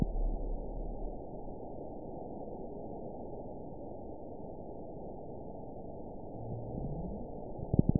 event 921909 date 12/22/24 time 15:51:17 GMT (4 months, 2 weeks ago) score 6.73 location TSS-AB04 detected by nrw target species NRW annotations +NRW Spectrogram: Frequency (kHz) vs. Time (s) audio not available .wav